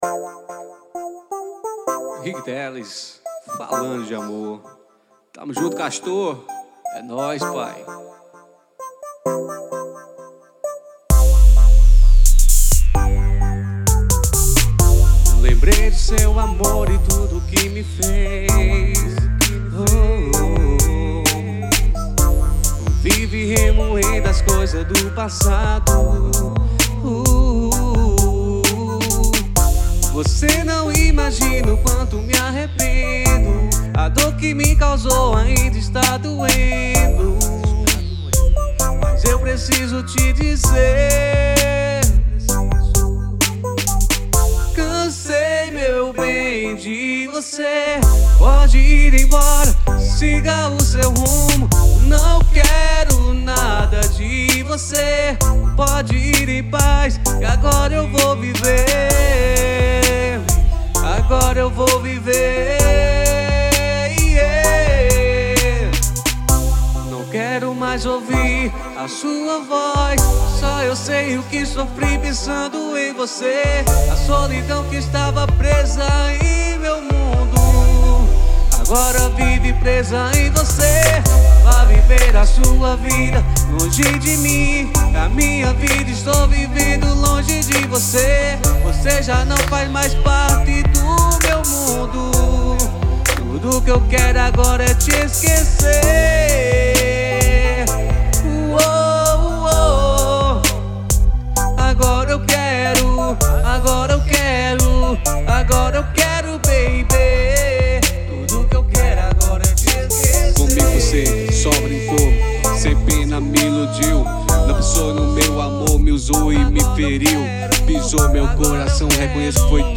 EstiloReggaeton